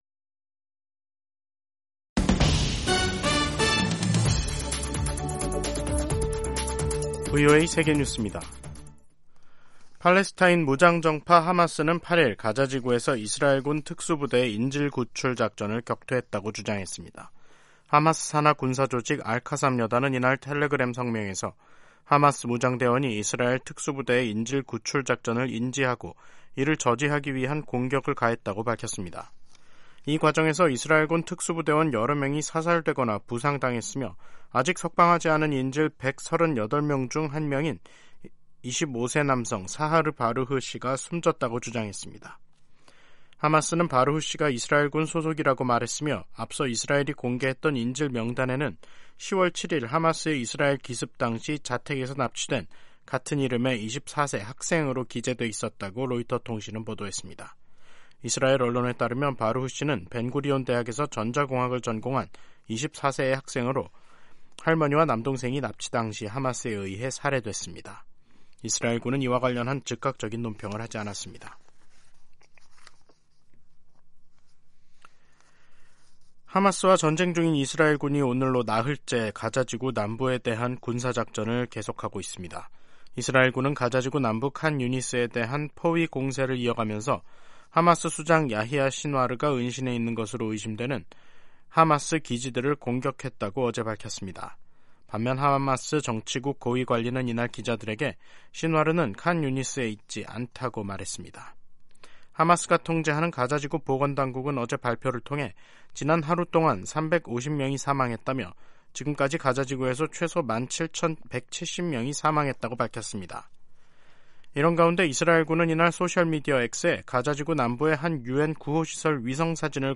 세계 뉴스와 함께 미국의 모든 것을 소개하는 '생방송 여기는 워싱턴입니다', 2023년 12월 8일 저녁 방송입니다. '지구촌 오늘'에서는 블라디미르 푸틴 러시아 대통령이 6일 사우디아라비아와 아랍에미리트(UAE) 지도자에 이어 7일에는 에브라힘 라이시 이란 대통령을 만나는 등 활발한 중동 외교를 펼친 소식 전해드리고, '아메리카 나우'에서는 공화당이 조 바이든 대통령 탄핵 조사 결의안을 발의한 이야기 살펴보겠습니다.